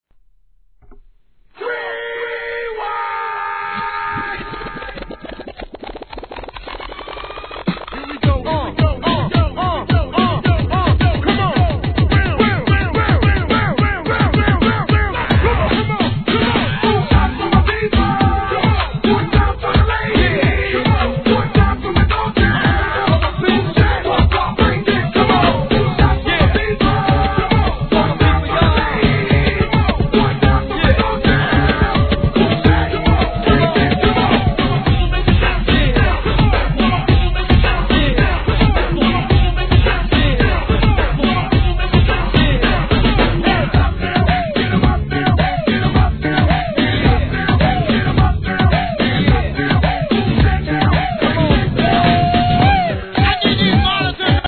HIP HOP/R&B
史上最強のアゲアゲチューンのみを収録した